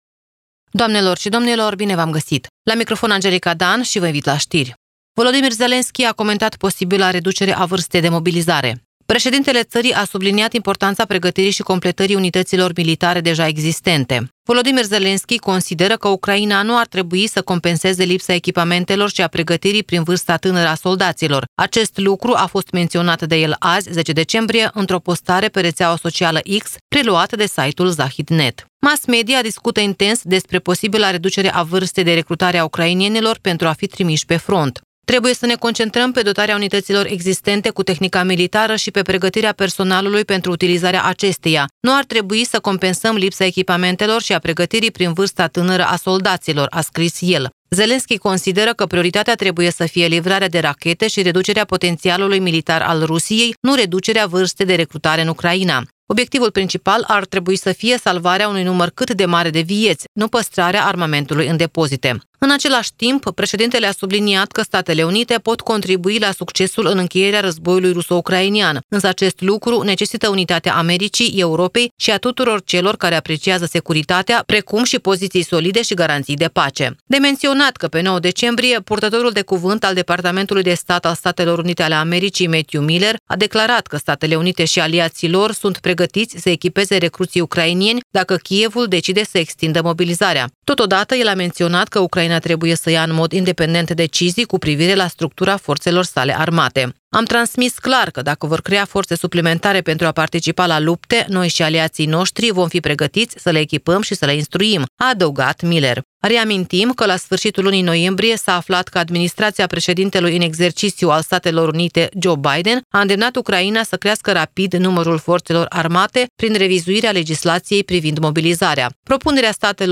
Știri Radio Ujgorod – 10.12.2024, ediția de seară
Știri de la Radio Ujgorod.